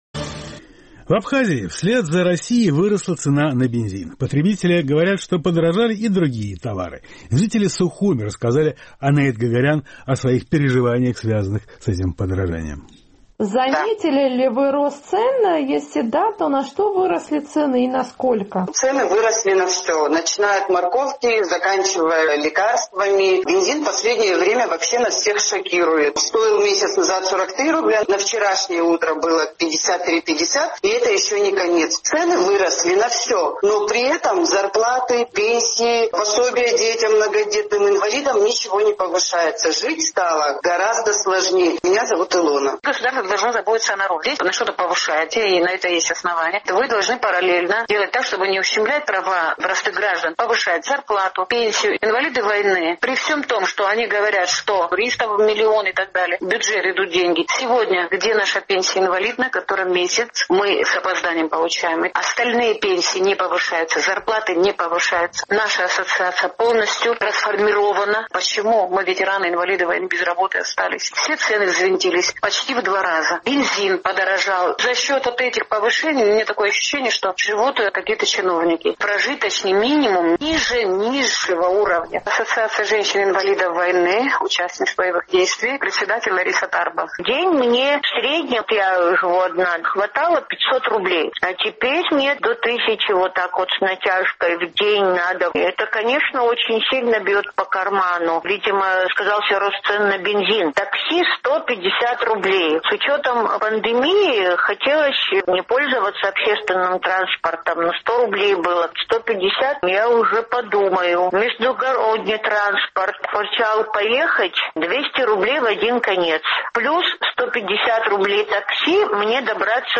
Жители Сухума рассказали «Эху Кавказа» о собственных наблюдениях.